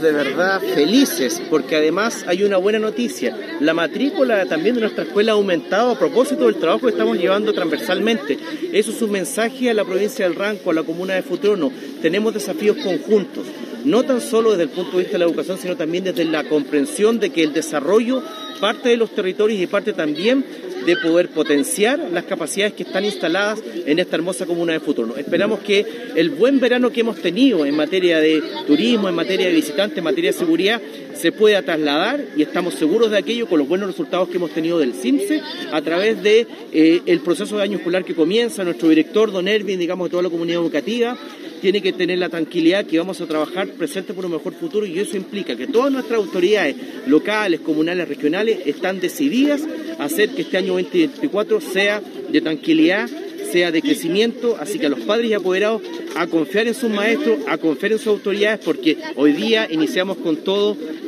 Con la ceremonia de inauguración de obras, se realizó el hito inicial del año escolar 2024 para la comuna de Futrono, relevando la inversión del Ministerio de Educación que supera los 277 millones.